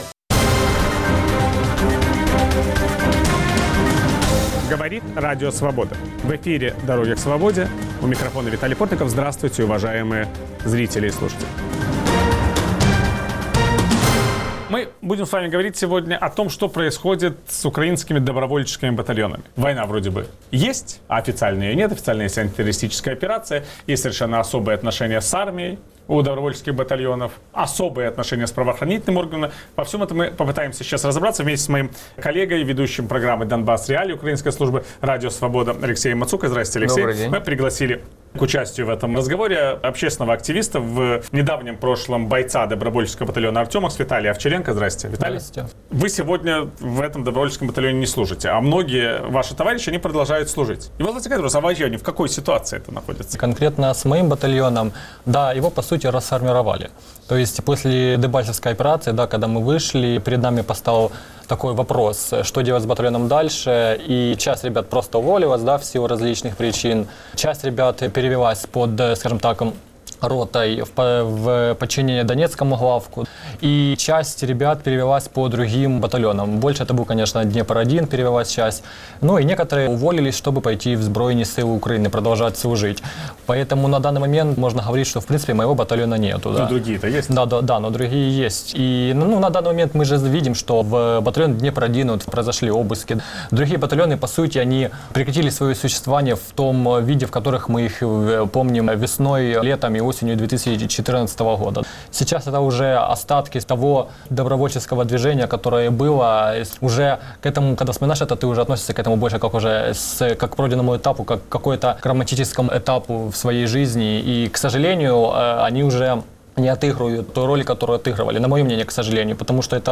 беседуют с бывшим бойцом добровольческого батальона